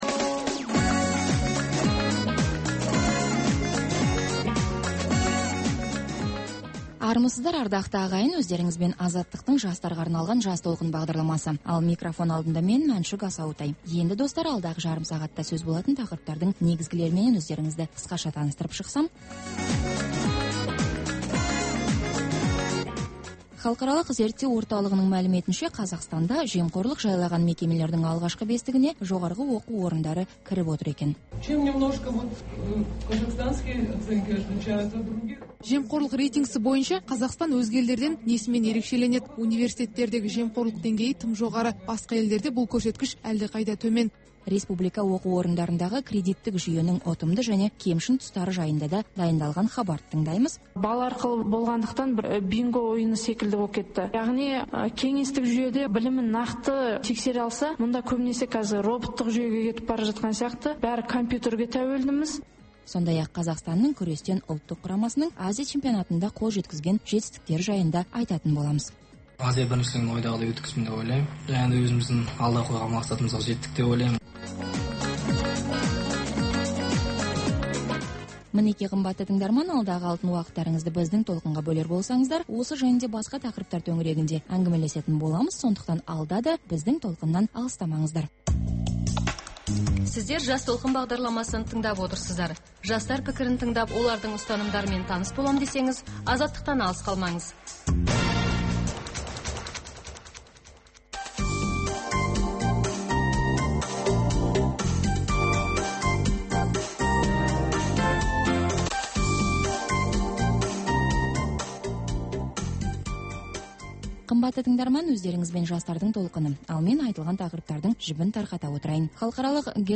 Қазақстан, Қырғызстан, Тәжікстан, Түркменстан және Өзбекстанда соңғы аптада болған маңызды оқиғалар мен құбылыстарды қысқаша шолу және талдау (сарапшылар, саясаткерлерді қатыстыра отырып), 5 республикадағы сөз, баспасөз бостандығының, дін еркіндігі және адам құқының ахуалына, халықтардың әлеуметтік жағдайына арналған сараптама мақалалар, көрші елдердің саяси, ғылыми, білім беру, мәдени салалардағы қарым-қатынасы туралы мақала-сұхбаттар, аймақ елдерінің экономикалық даму барысы туралы сараптама, болжамдар және рухани-мәдени тіршілігіндегі жаңалықтармен таныстырып отыру.